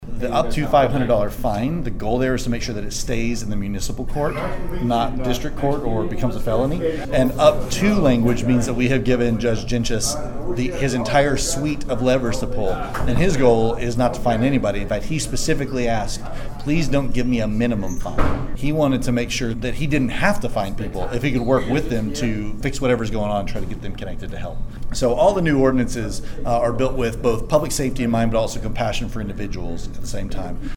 On Monday, the council approved an ordinance clearing up any language that may have been misunderstood regarding what can be done in public spaces. Council member Aaron Kirkpatrick is a member of the task force and he goes into more detail with what that means.
Kirkpatrick talks about how he best knows how to